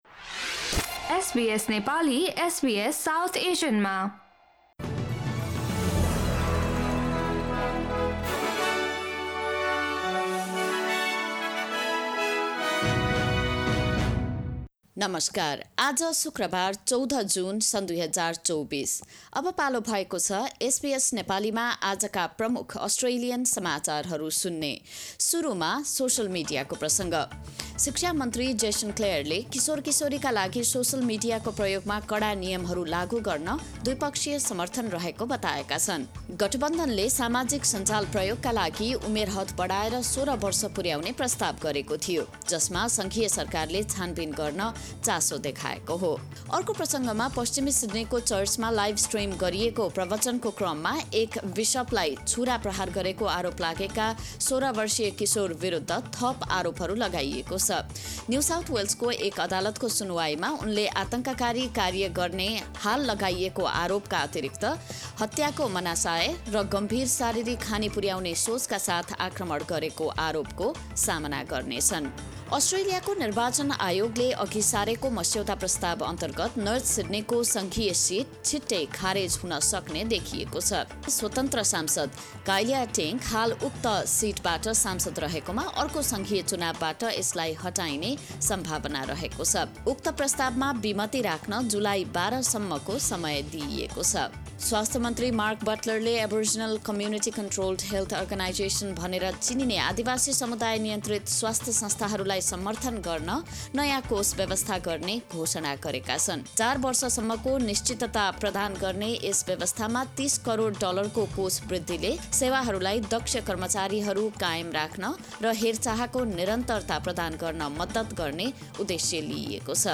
SBS Nepali Australian News Headlines: Friday, 14 June 2024